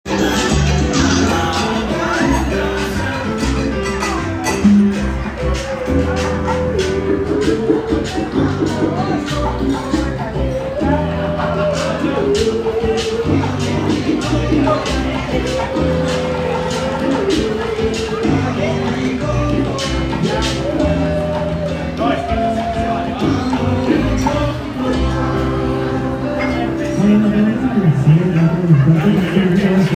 My first time eating out solo in a restaurant, in my travels but potentially in my life as well.
The pork was a bit too crispy for me but I enjoyed listening to the live band.